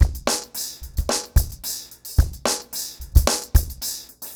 RemixedDrums_110BPM_15.wav